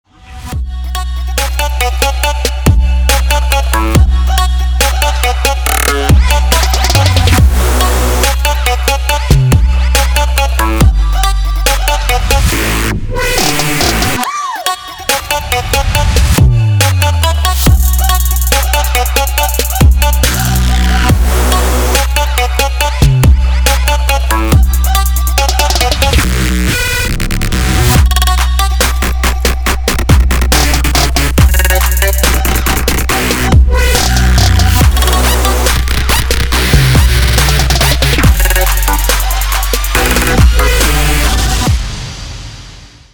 • Качество: 320, Stereo
громкие
без слов
качающие
Качающая мелодия на звонок!